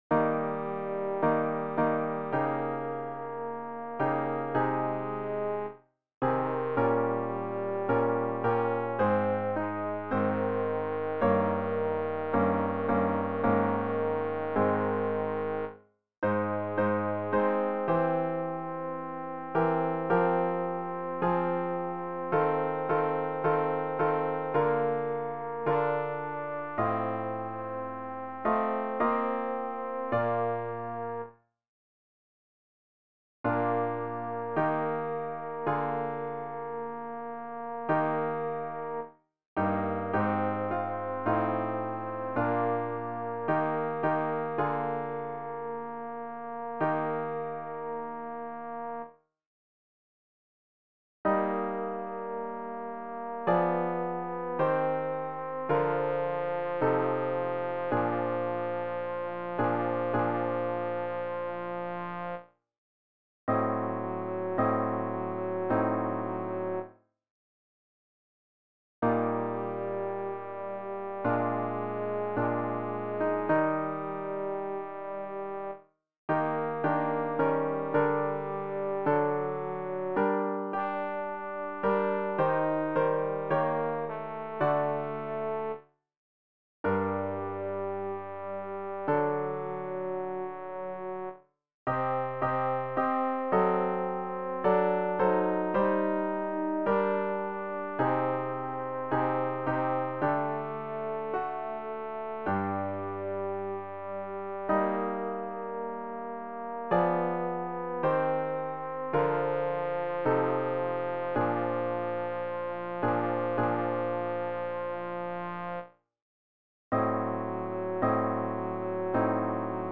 tenor-haydn-tenebrae-factae-sunt.mp3